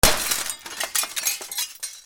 窓ガラスを割る
/ H｜バトル・武器・破壊 / H-45 ｜ガラス / 2_窓ガラスを割る
ガシャーン Edit 原音あり D50